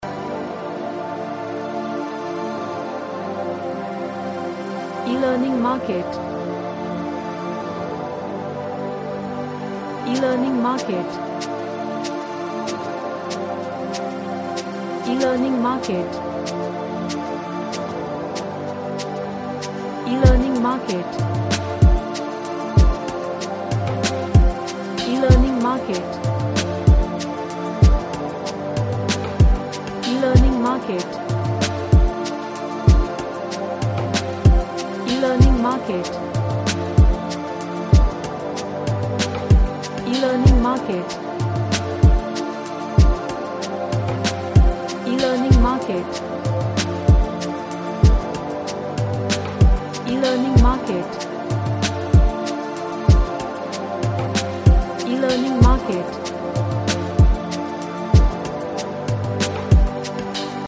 An Ambient Track with high frequency pads.
Relaxation / Meditation